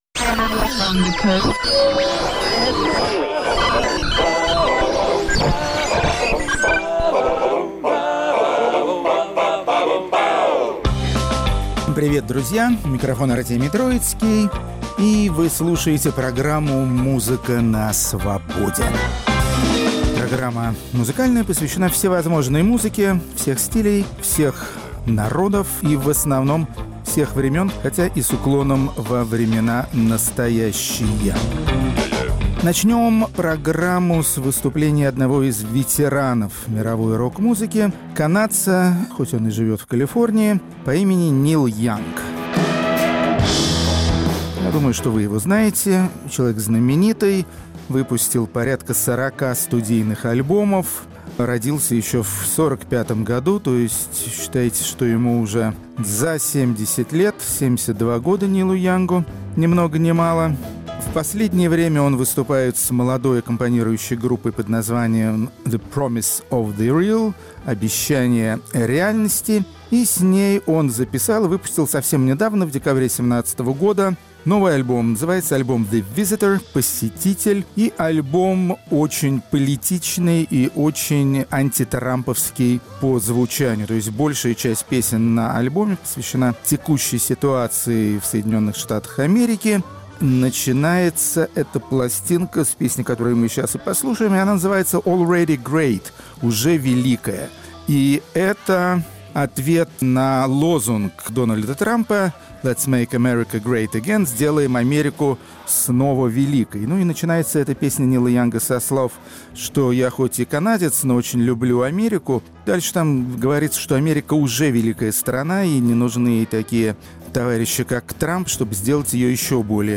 Хедлайнеры пятьдесят шестого выпуска программы "Музыка на Свободе" – южновьетнамские буржуазные музыканты 1970-х годов. Это раритетная по нынешним временам музыка, таких записей нигде не достать, и рок-критик Артемий Троицкий с удовольствием представляет вам звезд сайгонской эстрады.